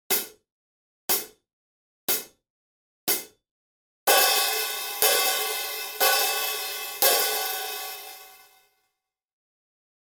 ZILDJIAN A ZILDJIAN MASTERSOUND HI-HAT BOTTOM 13